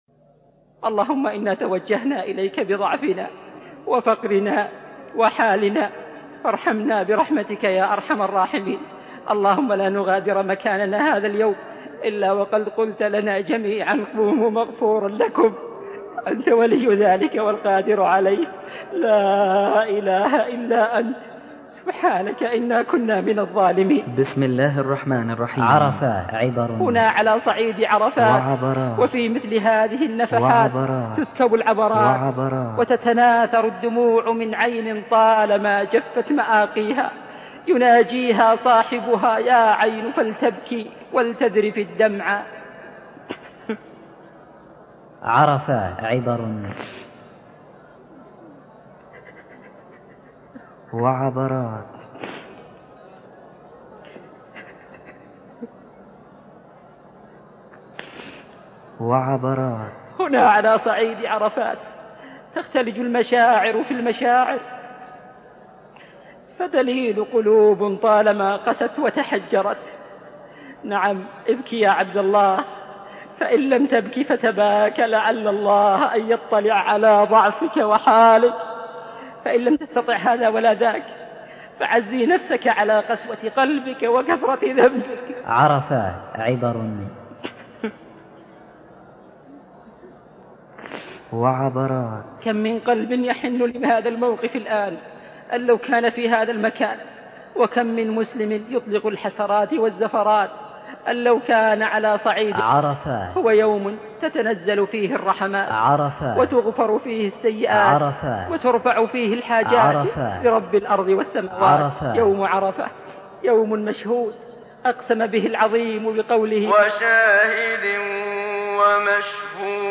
محاضراة